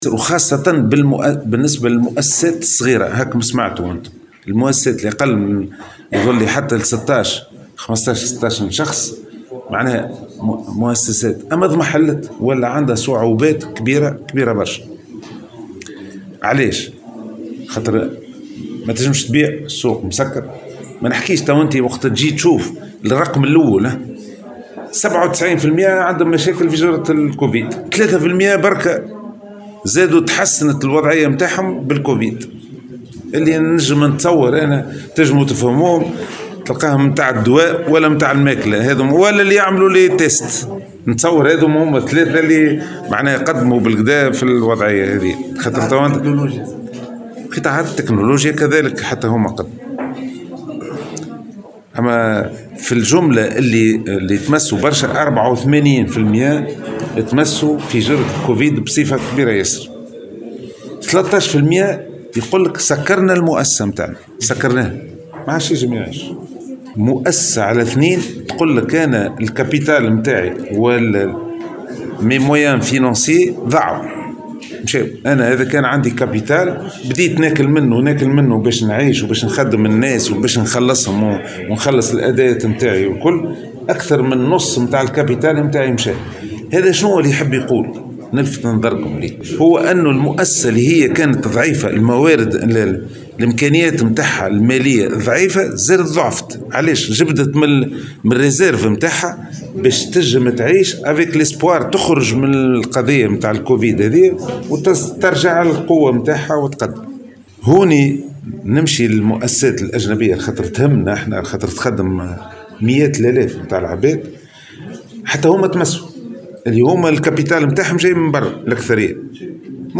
في تصريح للجوهرة اف ام